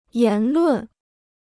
言论 (yán lùn): expression of opinion; views.
言论.mp3